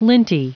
Prononciation du mot linty en anglais (fichier audio)